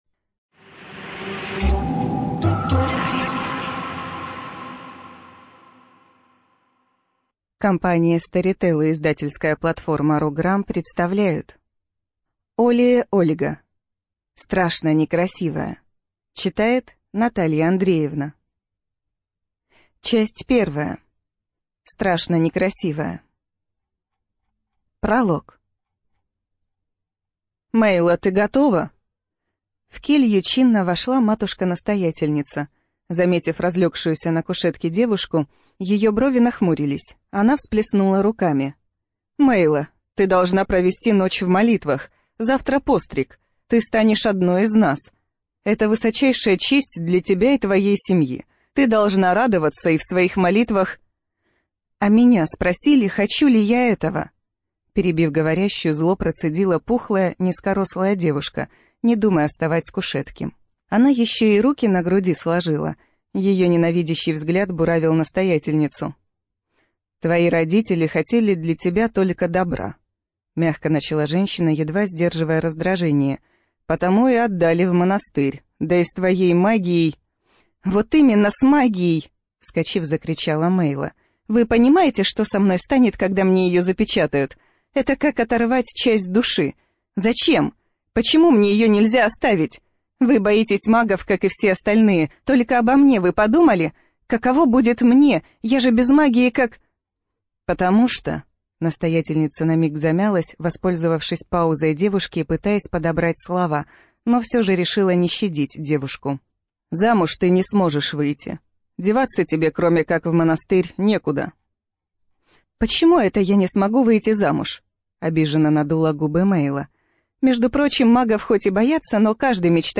Студия звукозаписиStorytel